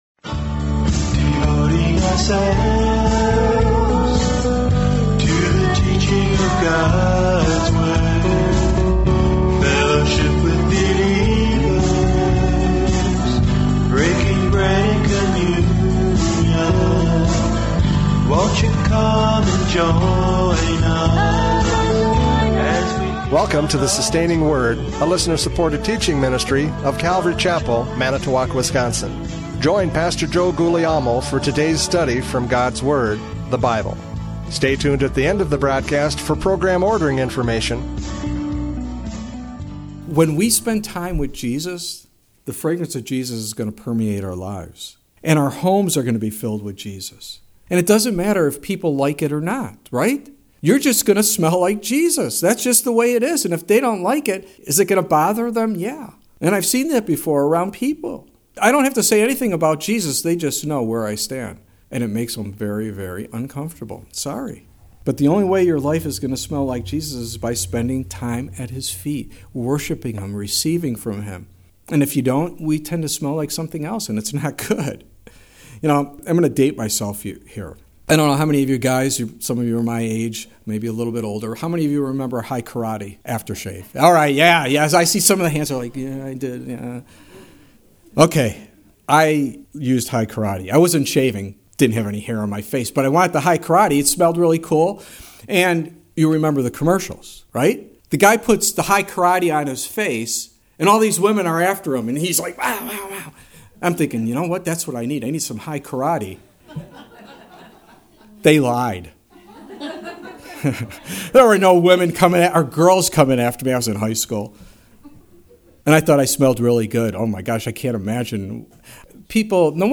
John 12:1-8 Service Type: Radio Programs « John 12:1-8 The Fragrance of Worship!